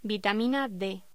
Locución: Vitamina D
voz